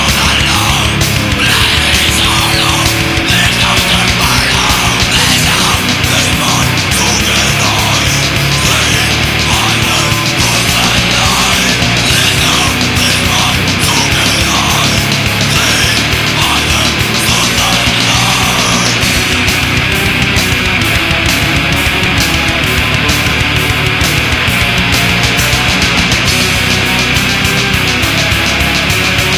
lo-fi 30 s. sample